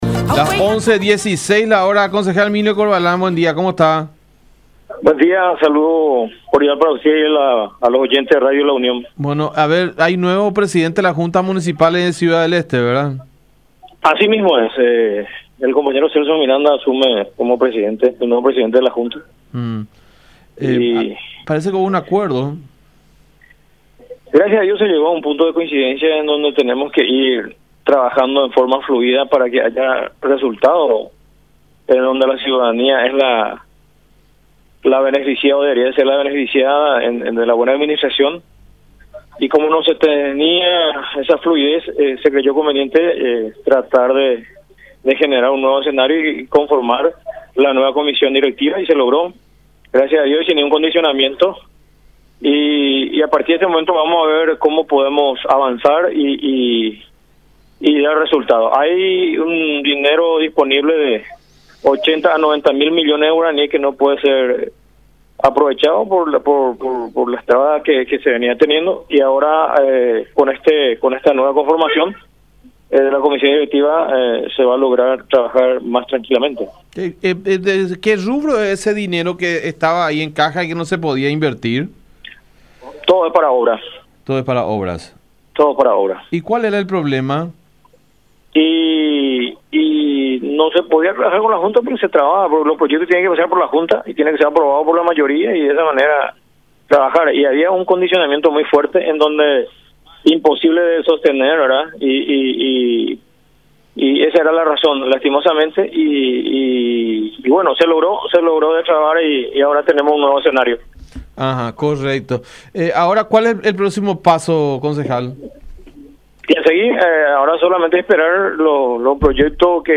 Por su parte, el edil Herminio Corvalán también habló al respecto y manifestó que “queremos poder trabajar de manera coordinada y en beneficio de la ciudadanía esteña”.
22-Herminio-Corvalán-Concejal-de-CDE.mp3